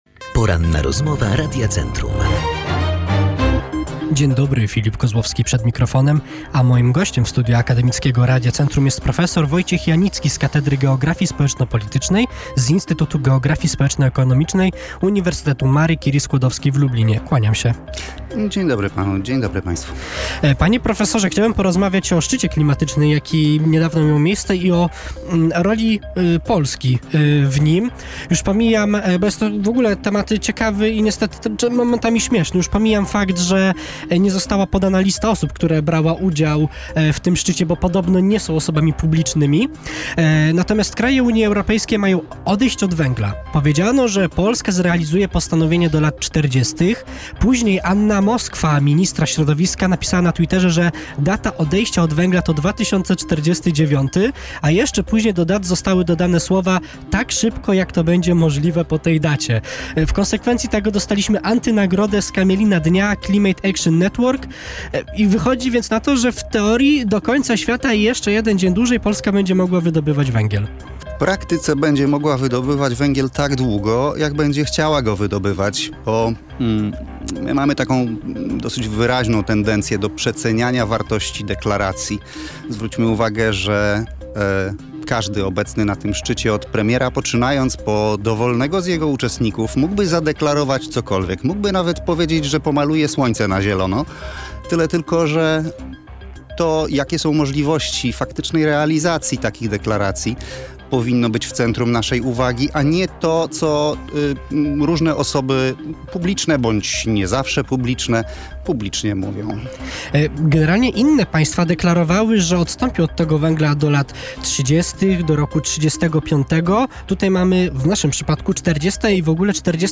Nasz rozmówca podkreśla, że w Polsce górnictwo jest nierentowne, więc takie postanowienia mogą podratować gospodarkę, a sytuację poprawiłyby nie tylko odnawialne źródła energii ale elektrownia atomowa. Całość rozmowy możecie przesłuchać poniżej: